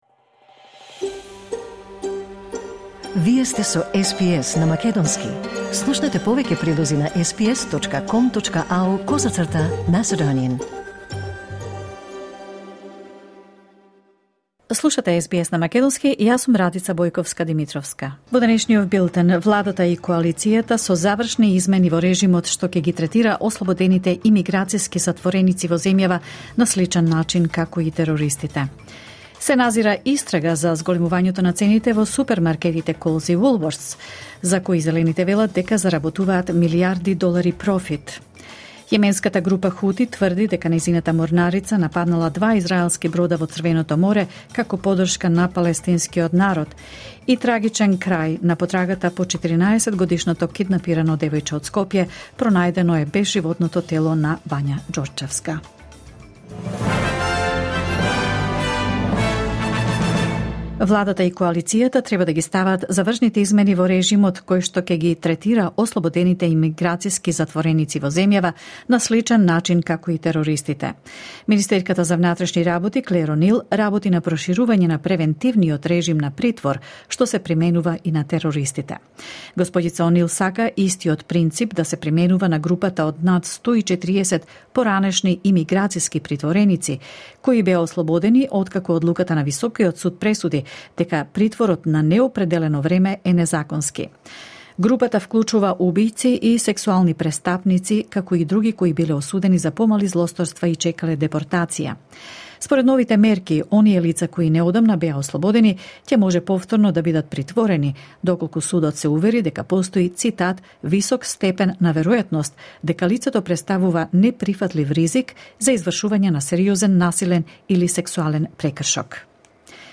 SBS News in Macedonian 4 December 2023